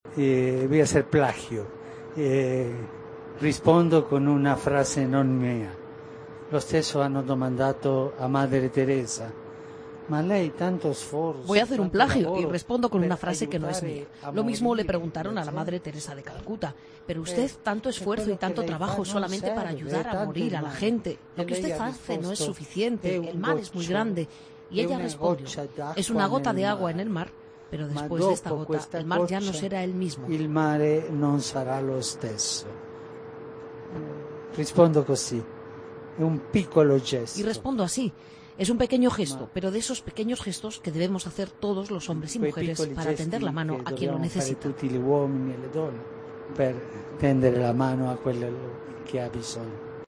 Palabras del Papa a su regreso de Lesbos en el vuelo de regreso a Lesbos